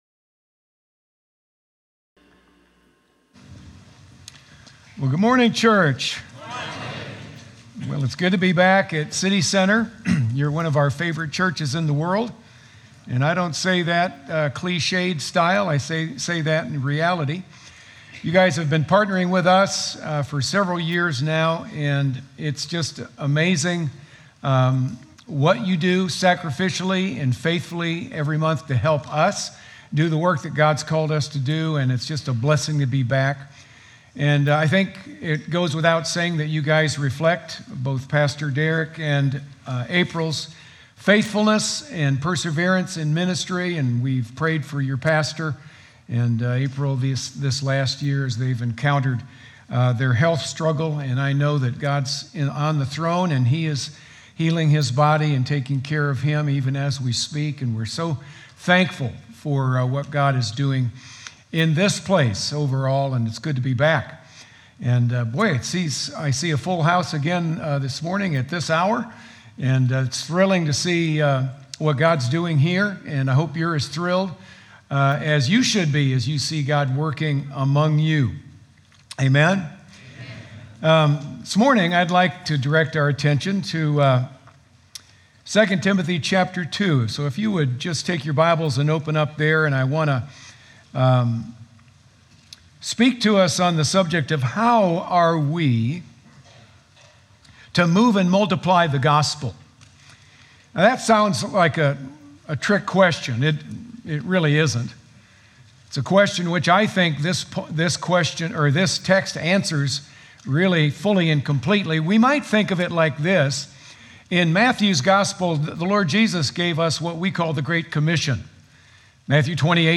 Sermon Notes: • Stand firm in spiritual battles (v. 1) • Equip and disciple others (v. 2) • Keep your eyes on what really matters (vv. 3–7)